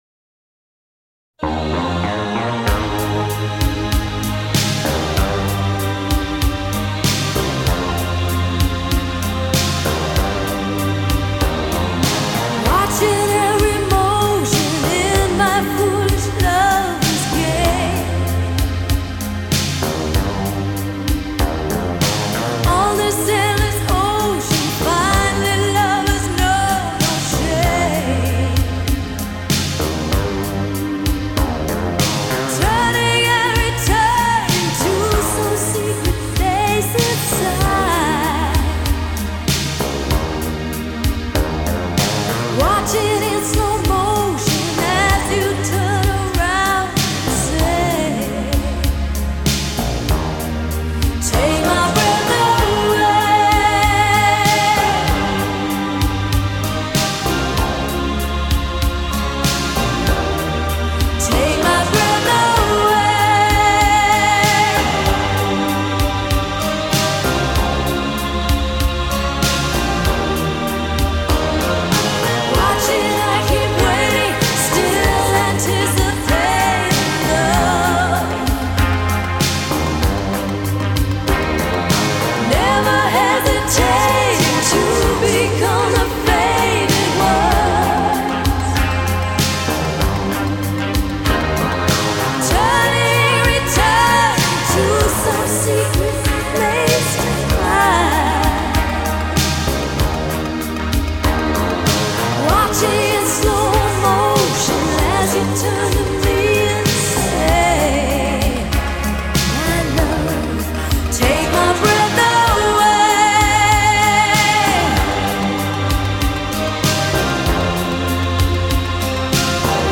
世界经典影片旋律